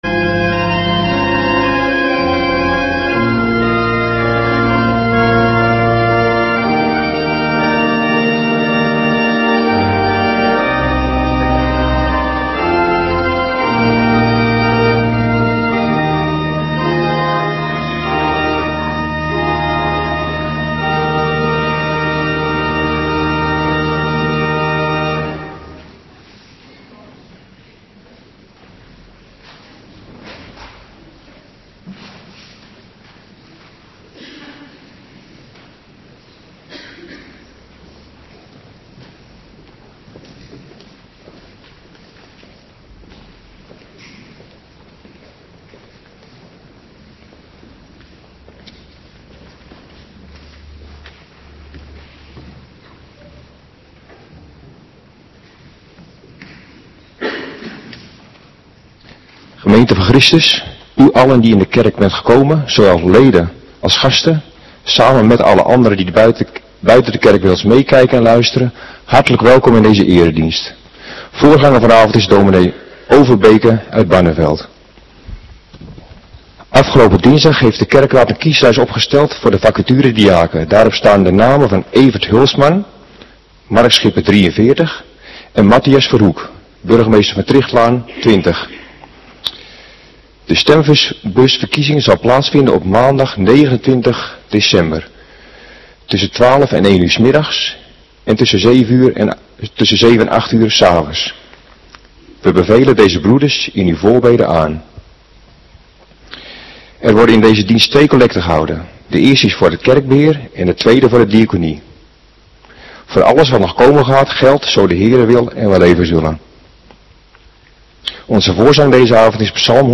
Avonddienst 14 december 2025